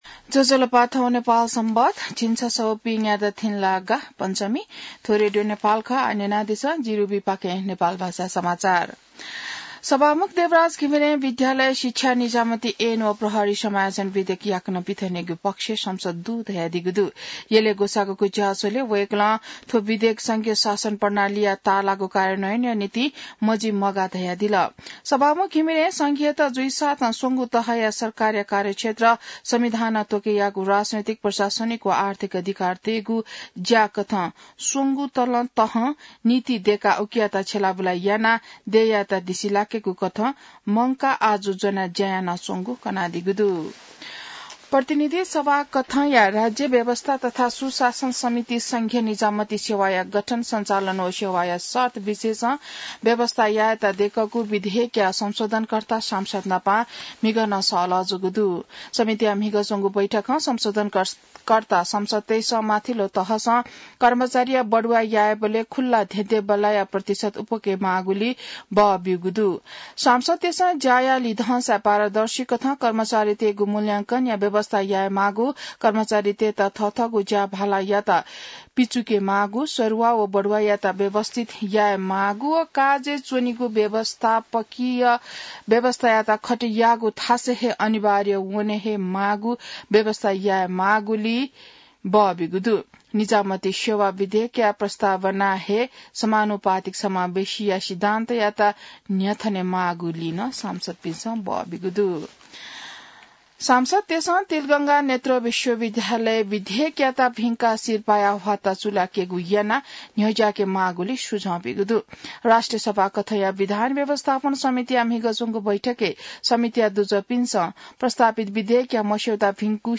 नेपाल भाषामा समाचार : ६ पुष , २०८१